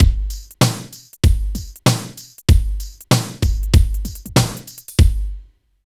28 DRUM LP-L.wav